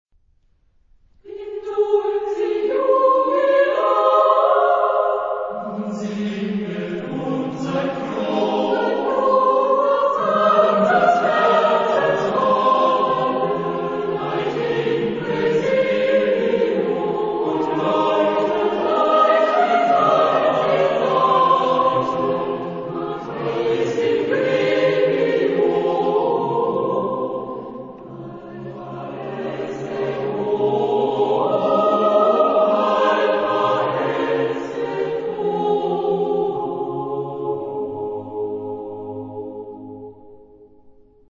Genre-Stil-Form: Liedsatz
Chorgattung: SATB  (4-stimmiger gemischter Chor )
Tonart(en): As-Dur